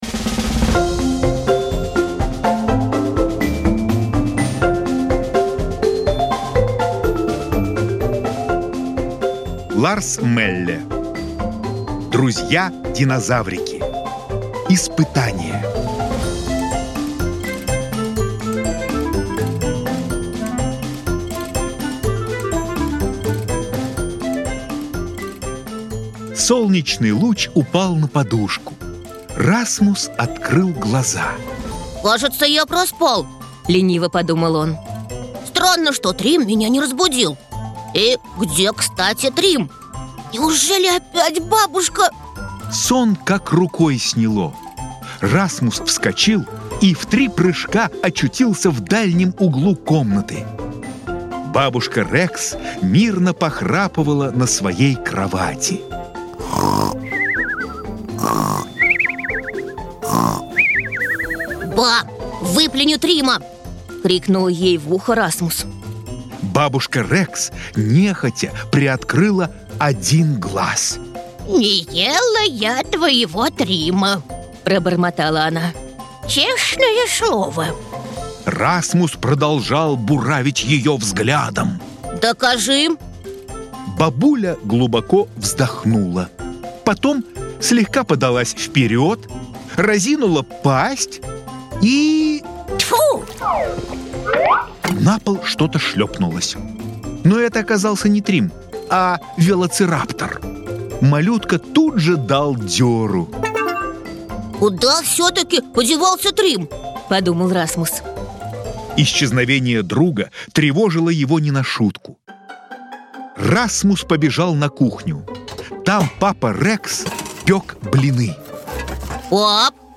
Аудиокнига Друзья-динозаврики. Испытание | Библиотека аудиокниг